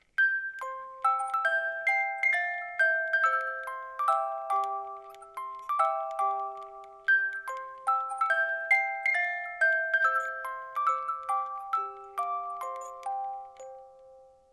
die Spieluhr besitzt ein Qualitätsspielwerk mit 18 Zungen